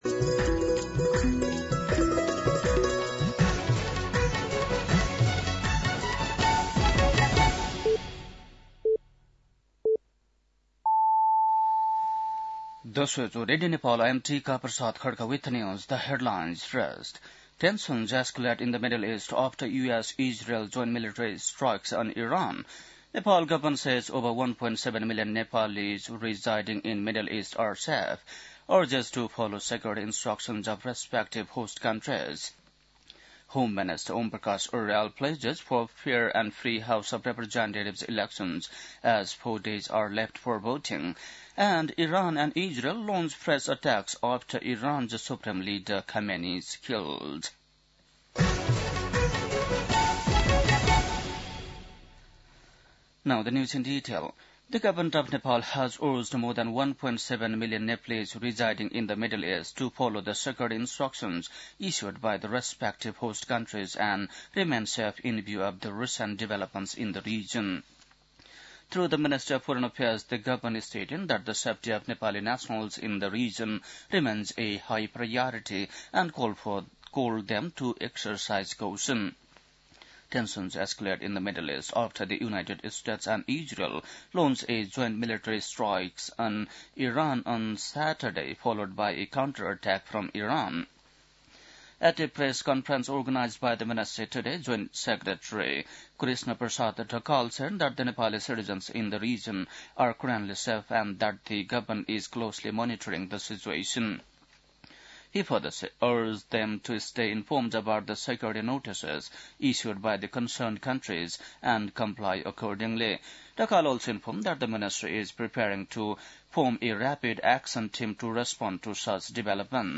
बेलुकी ८ बजेको अङ्ग्रेजी समाचार : १७ फागुन , २०८२
8-pm-english-news-11-17.mp3